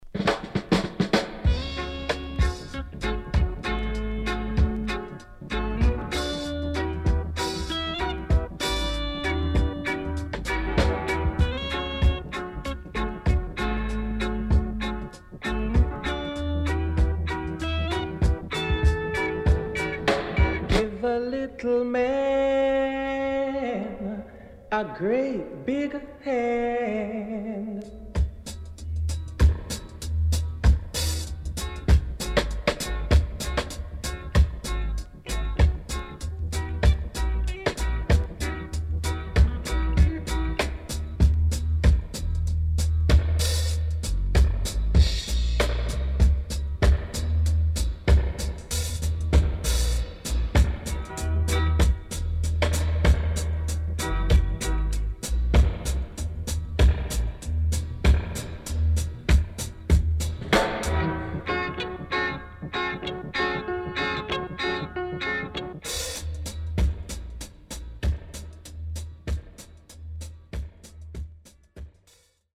HOME > REISSUE [REGGAE / ROOTS]
極甘なファルセットが気持ちいいNice Soulful Reggae.Recommend!!